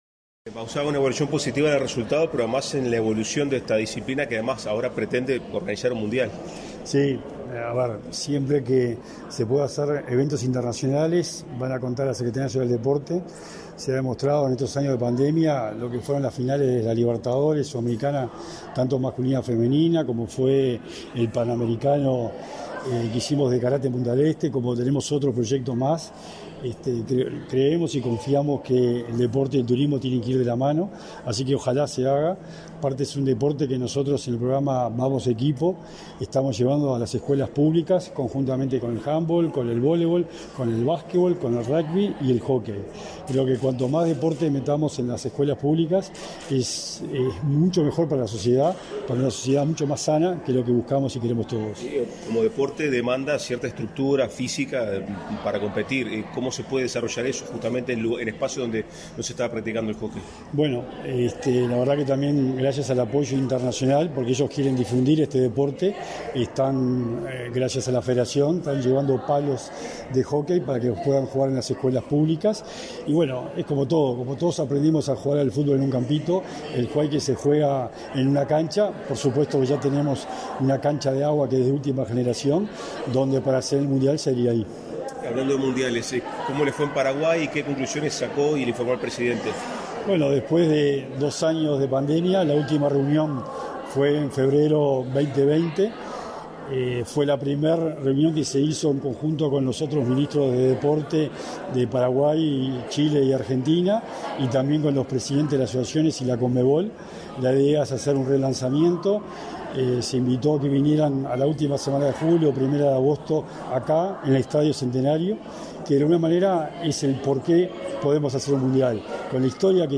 Declaraciones de prensa del secretario nacional del Deporte, Sebastián Bauzá
Declaraciones de prensa del secretario nacional del Deporte, Sebastián Bauzá 20/06/2022 Compartir Facebook X Copiar enlace WhatsApp LinkedIn Tras la conferencia de prensa en la que participó el presidente de la República, Luis Lacalle Pou, por el acto de reconocimiento a las Cimarronas, campeonas invictas del torneo FIH Hockey 5 Lausana 2022, Sebastián Bauzá efectuó declaraciones a la prensa.
bauza prensa.mp3